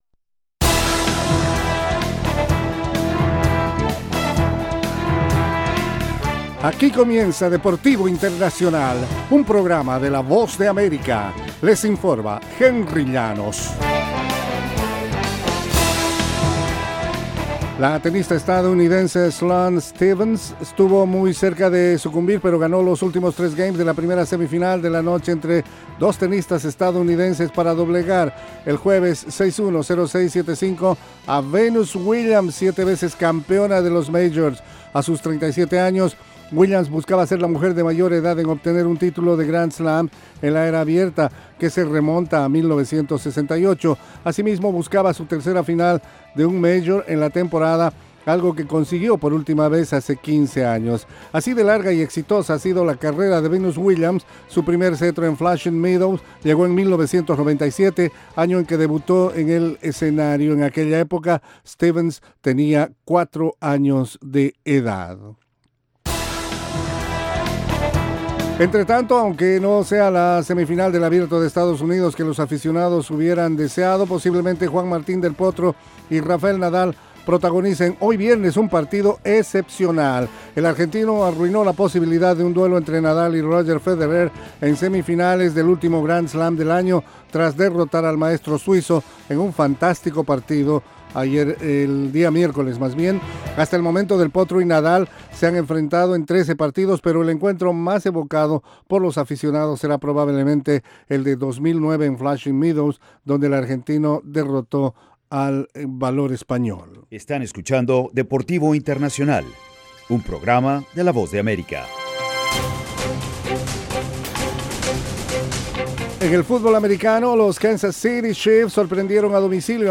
La información deportiva en cinco minutos, desde los estudios de la Voz de América.